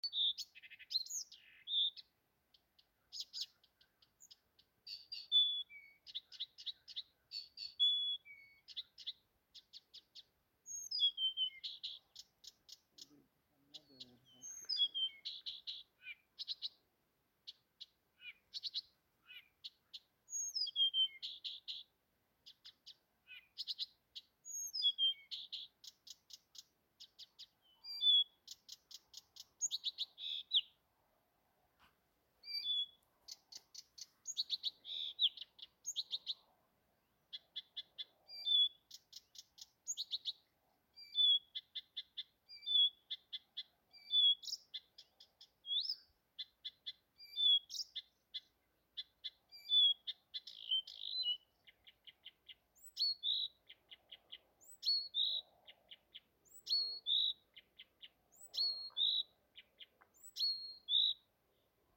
Птицы -> Славковые -> 1
садовая камышевка, Acrocephalus dumetorum
СтатусПоёт